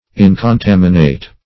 Search Result for " incontaminate" : The Collaborative International Dictionary of English v.0.48: Incontaminate \In`con*tam"i*nate\, a. [L. incontaminatus.